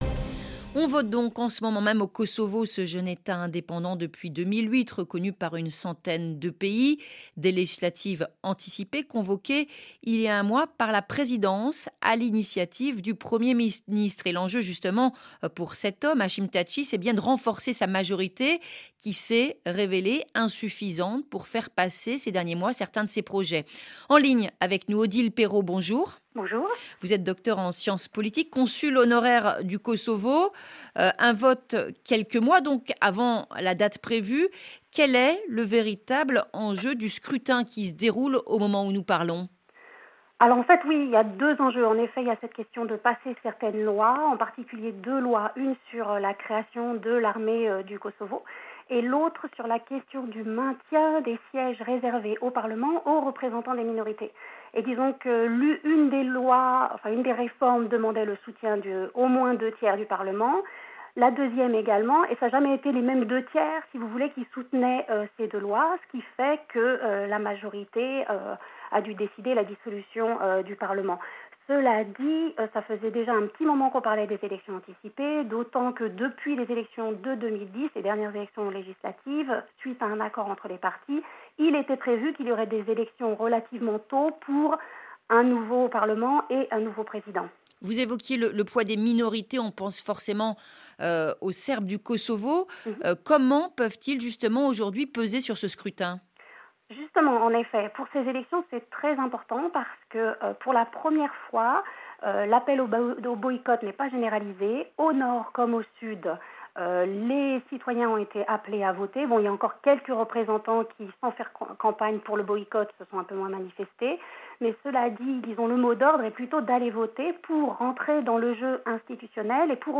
Interview RFI 8 juin 2014